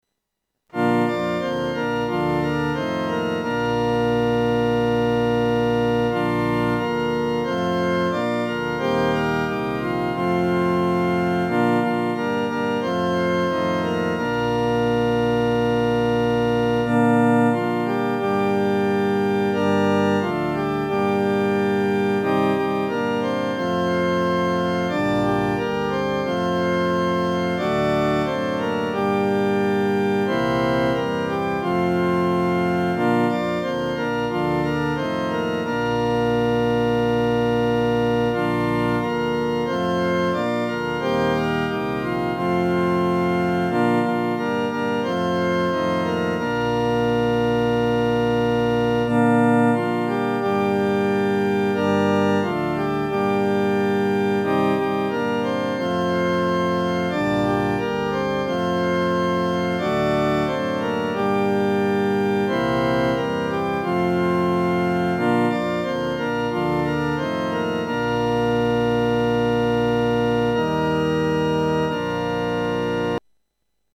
伴奏
四声
曲调很朴素，用的是五声音阶，农村信徒很容易上口。